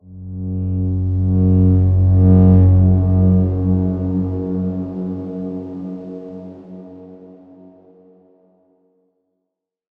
X_Darkswarm-F#1-pp.wav